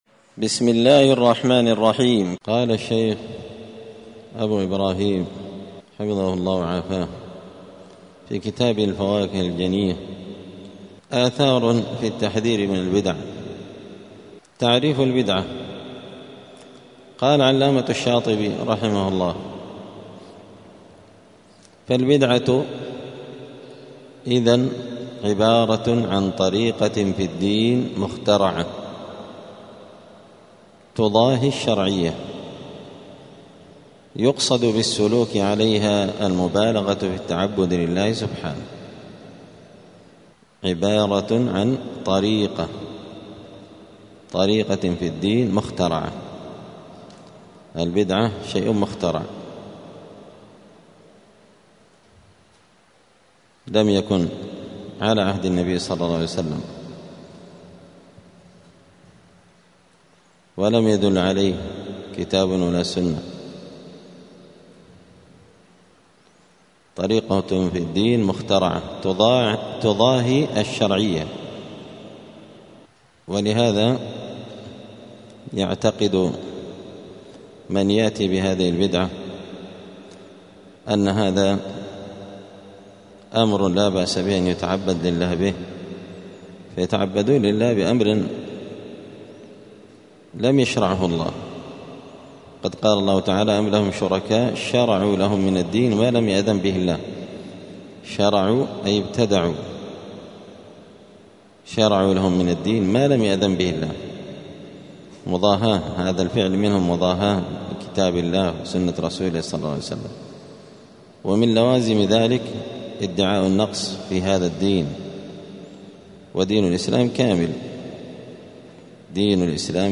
دار الحديث السلفية بمسجد الفرقان بقشن المهرة اليمن
*الدرس الرابع والسبعون (74) {آثار في البدعة}*